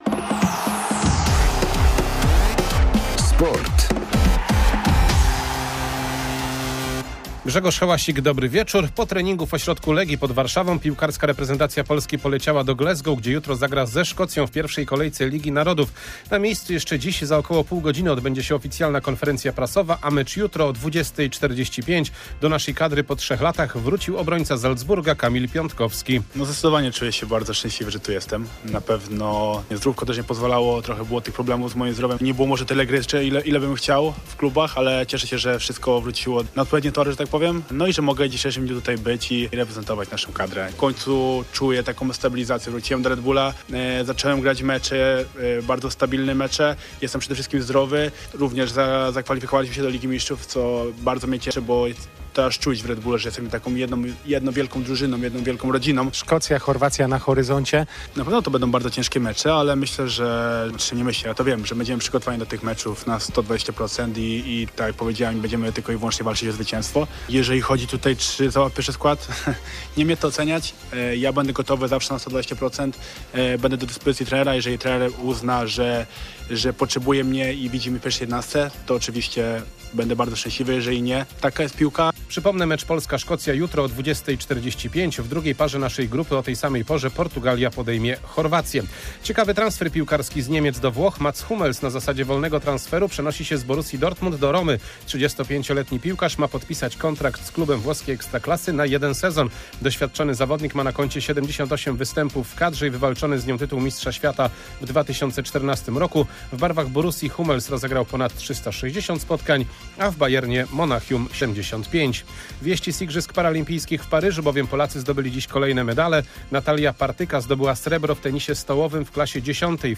04.09.2024 SERWIS SPORTOWY GODZ. 19:05